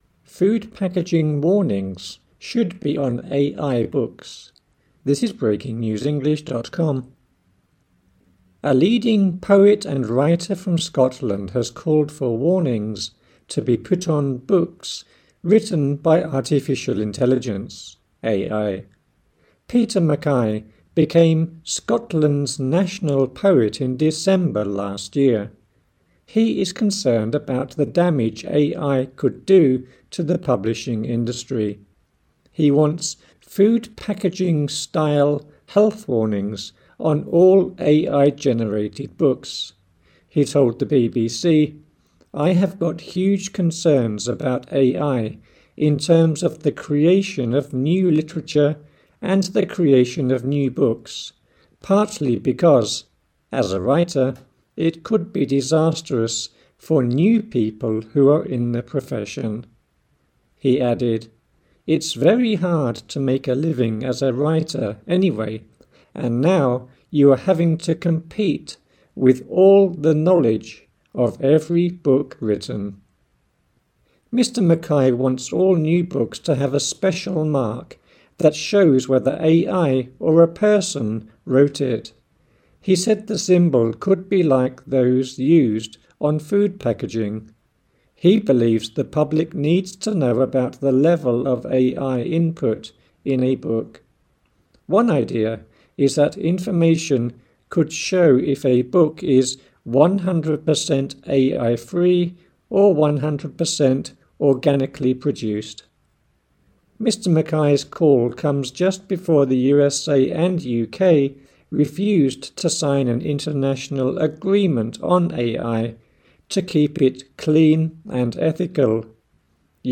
AUDIO (Slow)